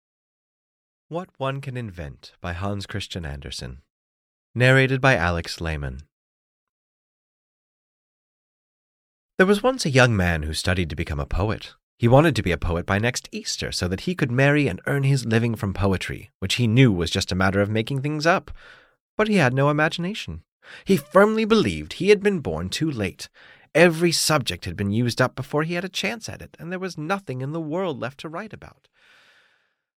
What One Can Invent (EN) audiokniha
Ukázka z knihy